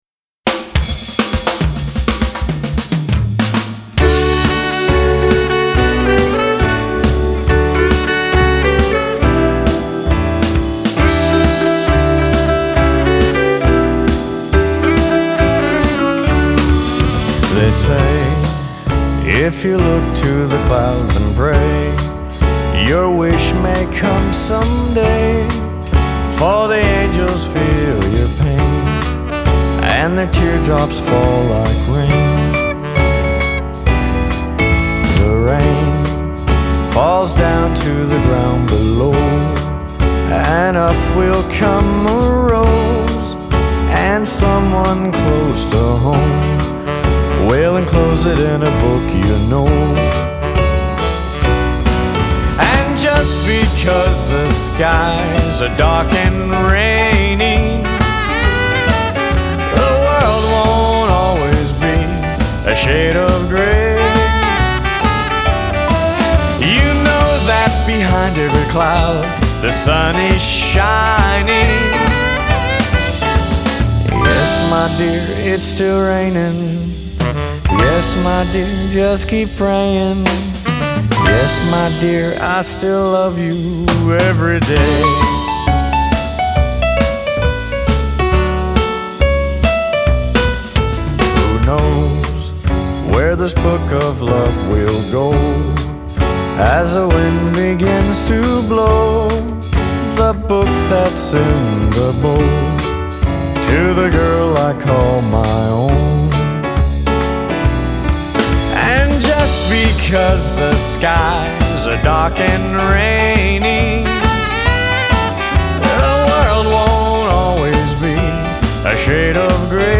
Vocals & Guitar
Bass & Vocals
Lead Guitar
Drums
Sax